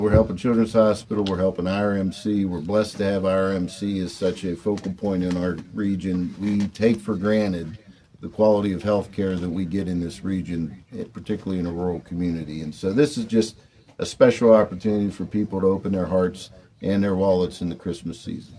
Senator Pittman talked about why it was important to support the fund drive.